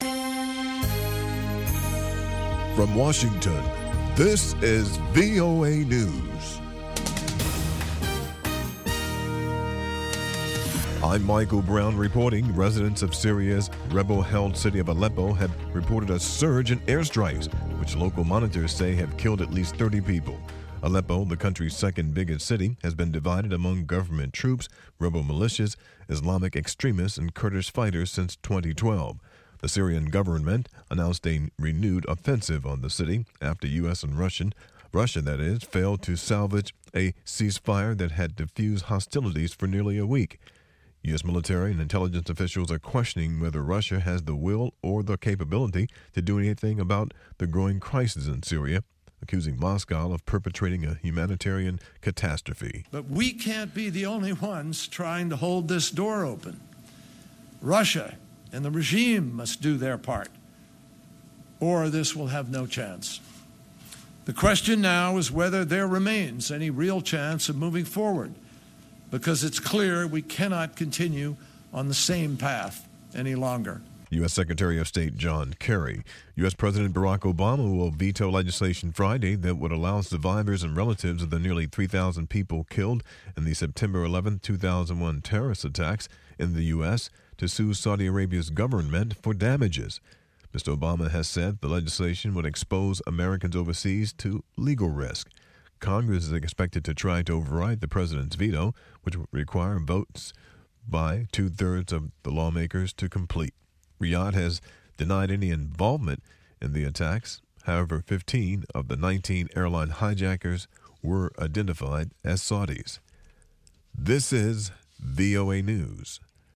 1600 UTC Newscast for September 23, 2016